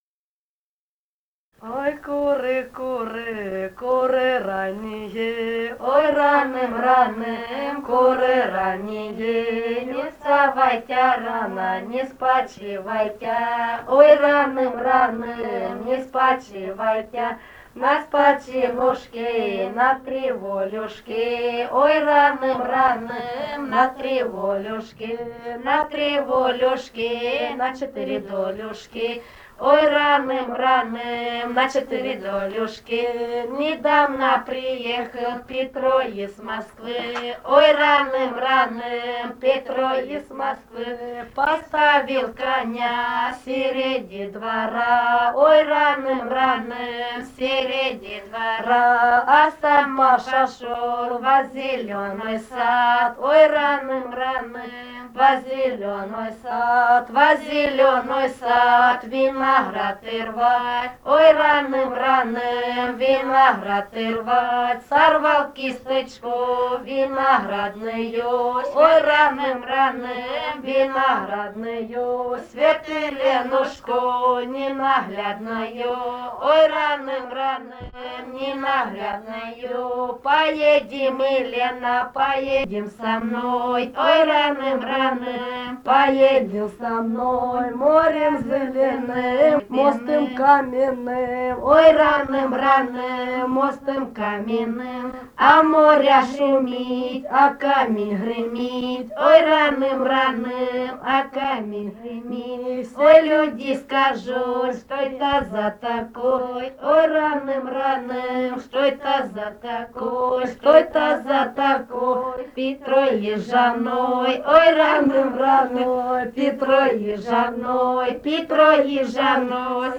полевые материалы
«Ой, куры, куры» (свадебная).
Румыния, с. Переправа, 1967 г. И0974-01